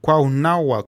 Cuernavaca (Spanish pronunciation: [kweɾnaˈβaka] ; Classical Nahuatl: Cuauhnāhuac [kʷawˈnaːwak], "near the woods"
modern pronunciation, Otomi: Ñu'iza) is the capital and largest city of the state of Morelos in Mexico.